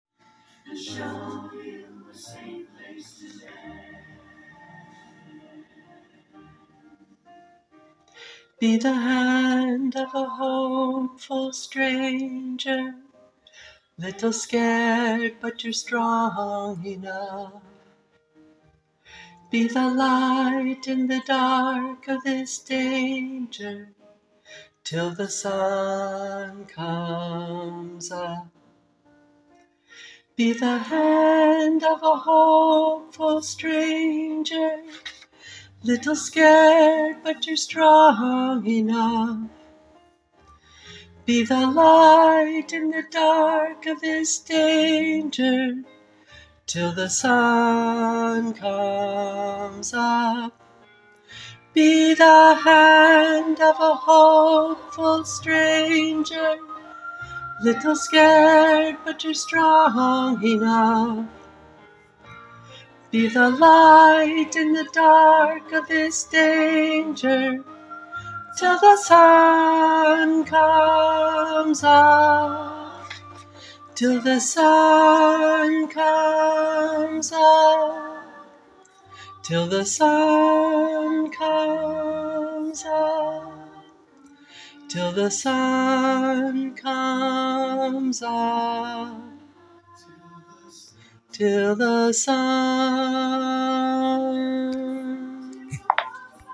[Choir] Practice recordings - be the hand
Name: tenor1 be the hand.mp3